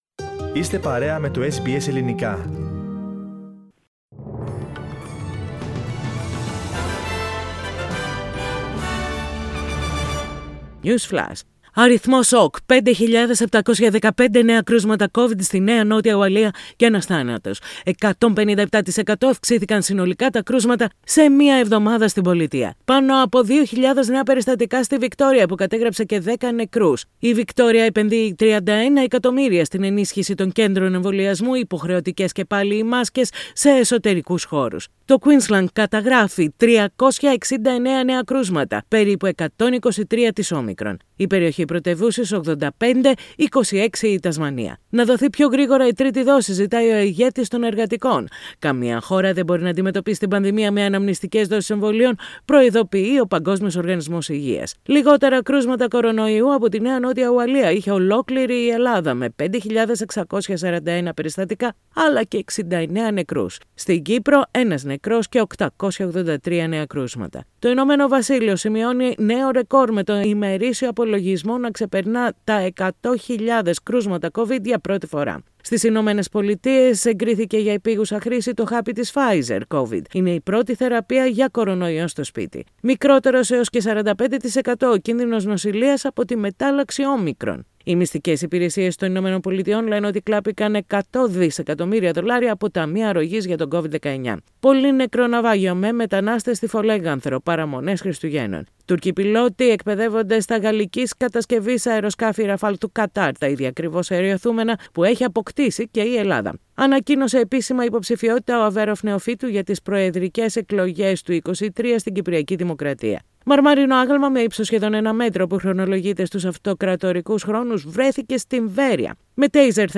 News Flash in Greek.